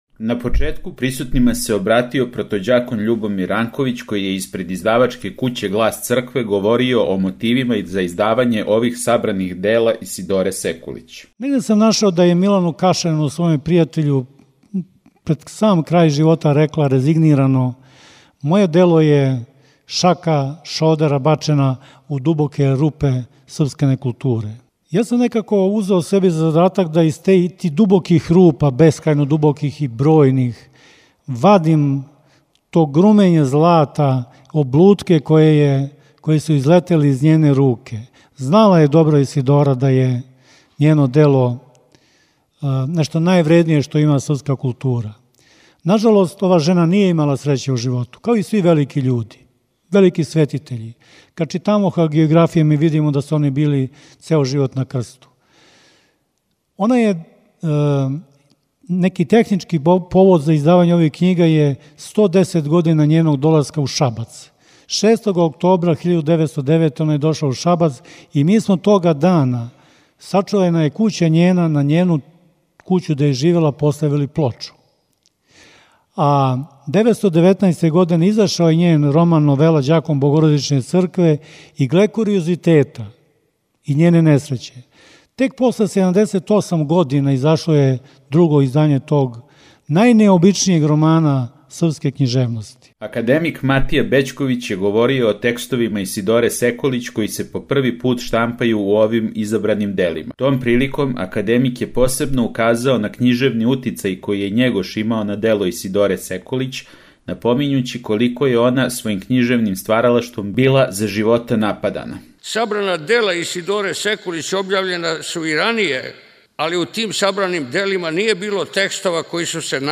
У уметничком делу програма наступила је драмска уметница Биљана Ђуровић, која је читала одломке из дела Исидоре Секулић.